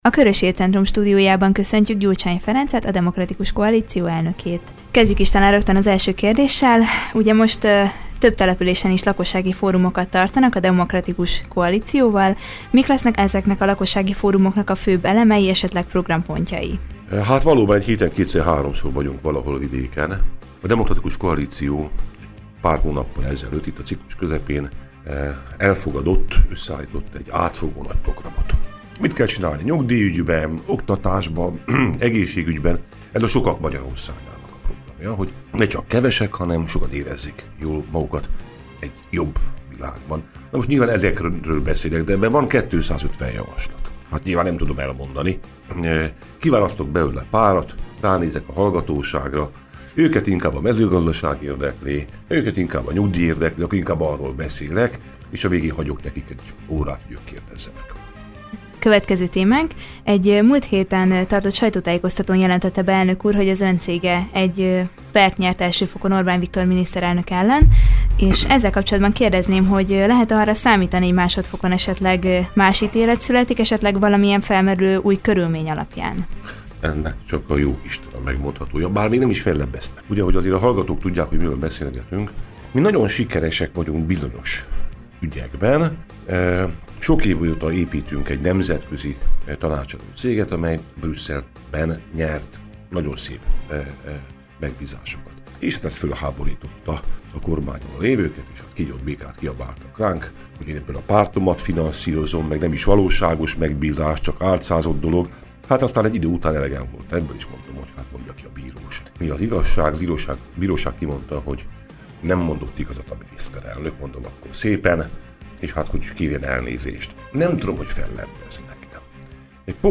A fórumot megelőzően, a Körös Hírcentrum stúdiójában az alábbi témakörökkel kapcsolatban kérdezte tudósítónk.